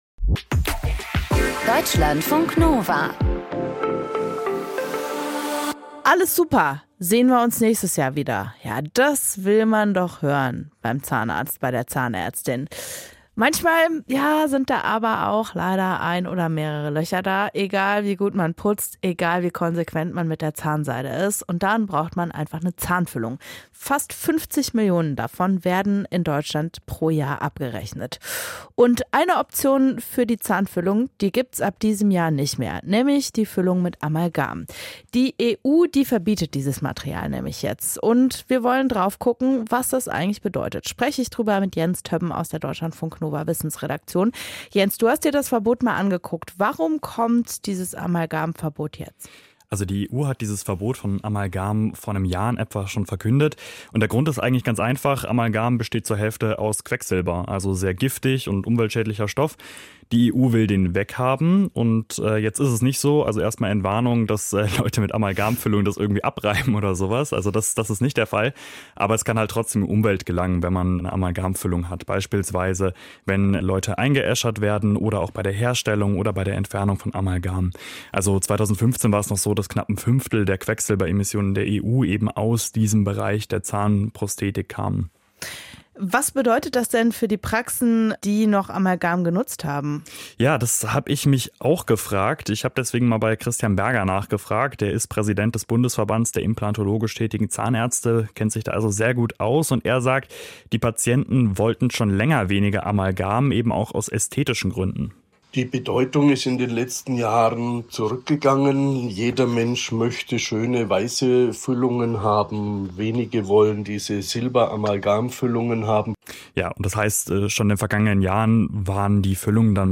Im Kompressor-Wochentalk fühlen wir dem Phänomen auf den Zahn.